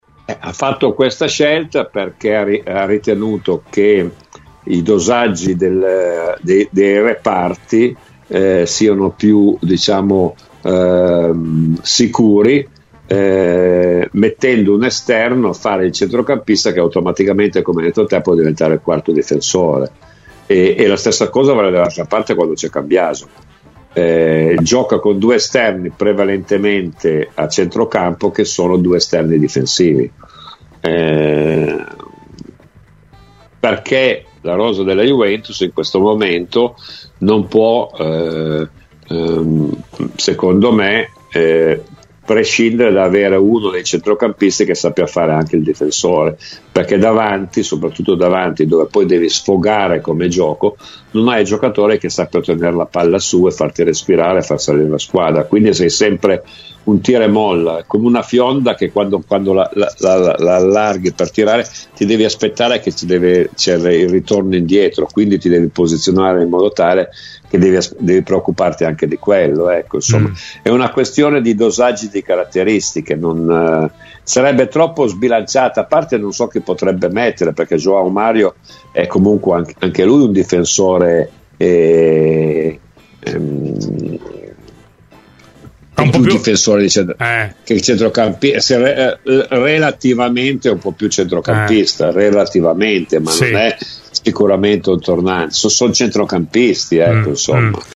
A due giorni dalla sfida con il Como, Domenico Marocchino ha parlato delle sue esperienze contro i Lariani: “Contro il Como ho un paio di ricordi, sia da avversario in B con la maglia della Cremonese, che in A con quella della Juventus – racconta a “Cose di Calcio”, su Radio BianconeraMi marcò in entrambi i casi Pietro Vierchowood, che rispetto tantissimo e ritengo un gran difensore, ma con me aveva spesso difficoltà, per cui degli scontri col Como ho un bel ricordo”.